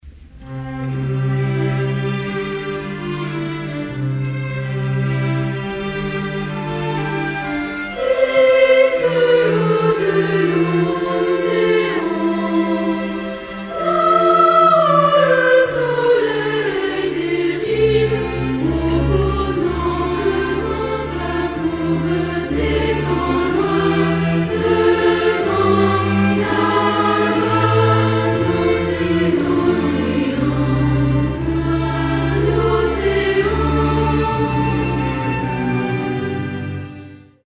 "L'âme à fleur d'eau" pour orchestre à cordes, voix de femmes et d'enfants.
aquarium.mp3